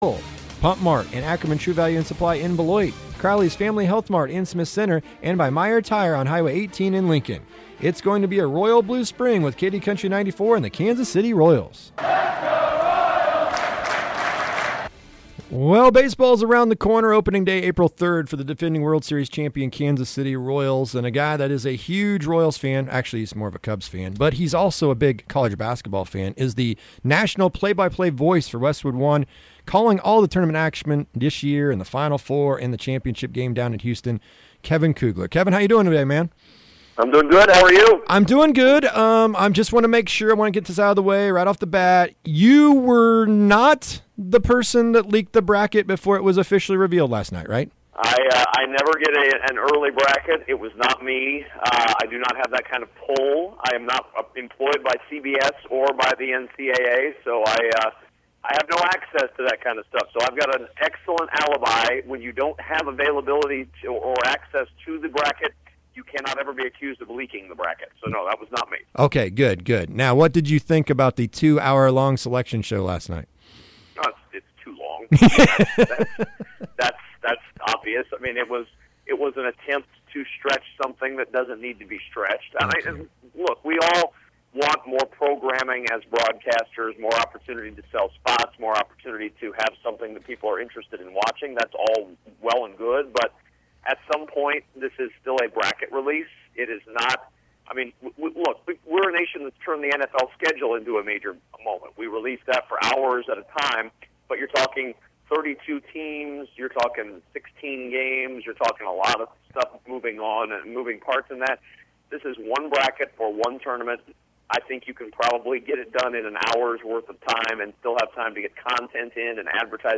Sports Ticket: Kevin Kugler Interview